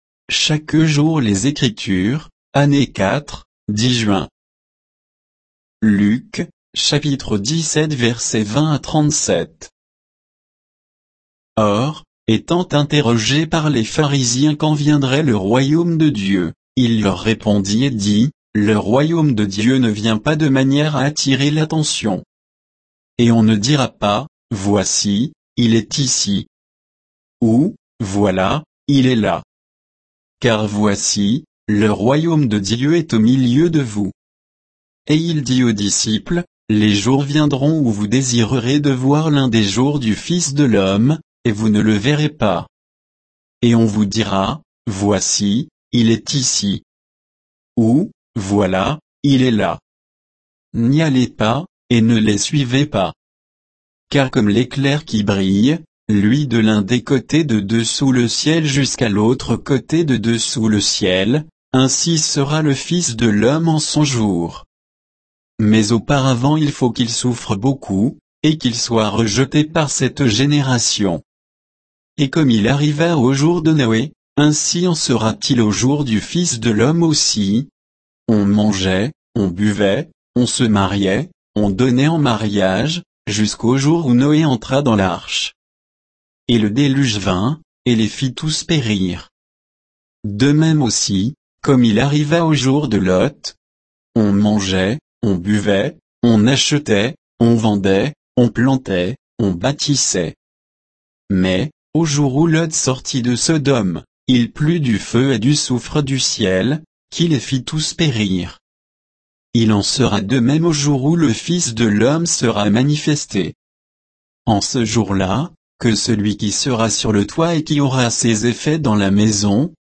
Méditation quoditienne de Chaque jour les Écritures sur Luc 17